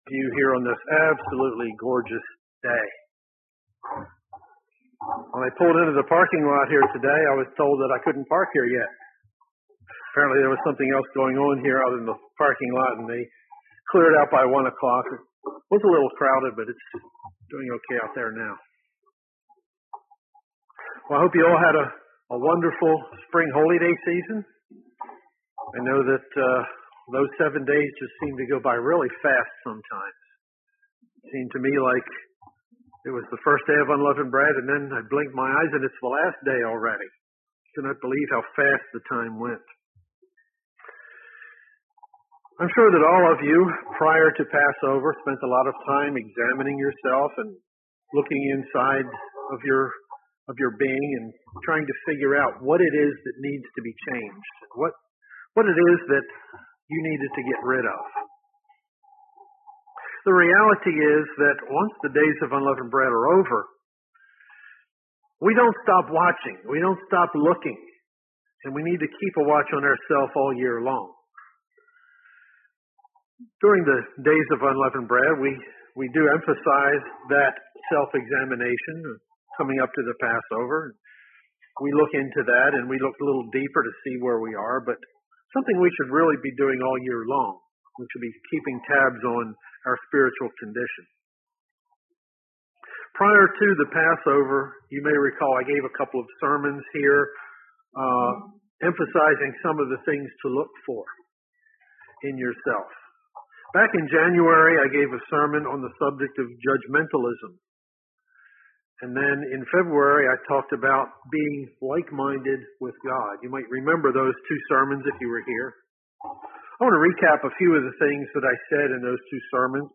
Print We must remember to first consider our own faults, not everyone else's. UCG Sermon Studying the bible?